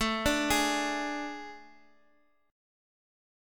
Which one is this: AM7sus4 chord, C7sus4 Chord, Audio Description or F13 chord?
AM7sus4 chord